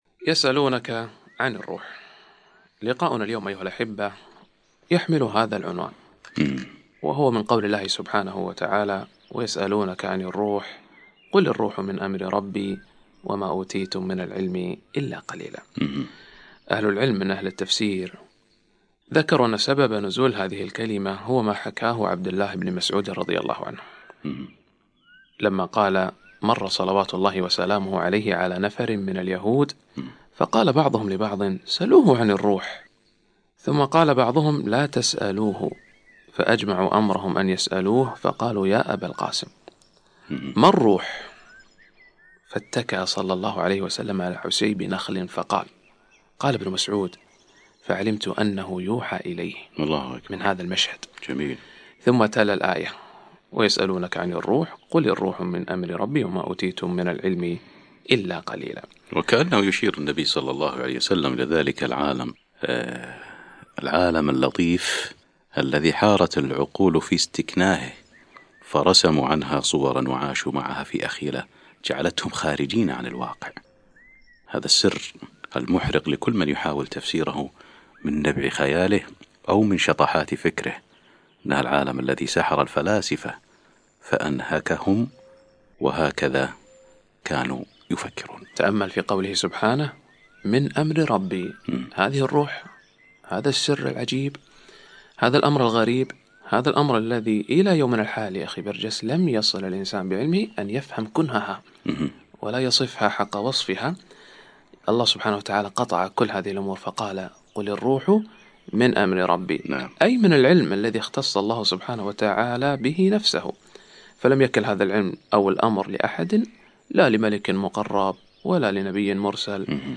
الروح من آيات الله وحججه - كلمة أذيعت في إذاعة القرآن الكويت